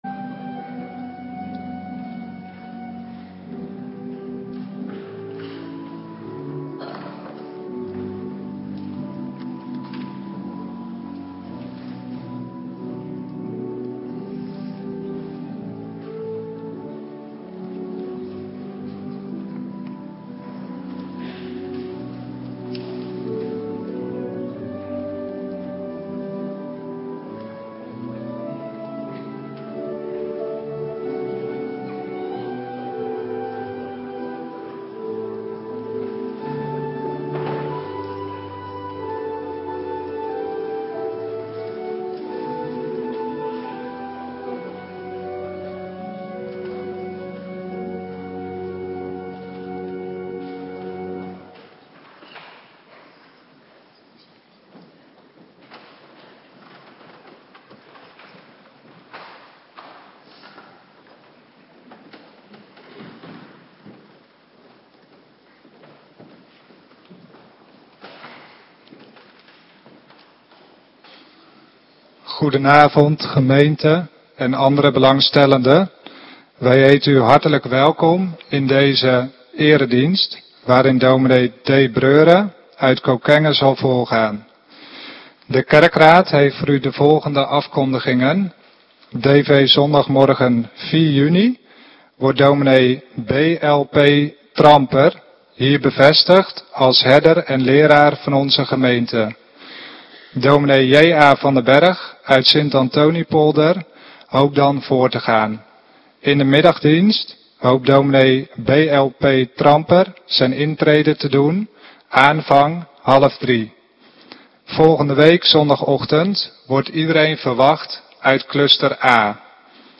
Avonddienst - Cluster A
Locatie: Hervormde Gemeente Waarder